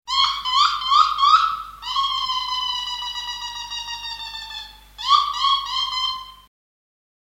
Animals
Kingfisher(116K) -Chickin(116K)